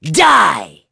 Neraxis-Vox_Skill1.wav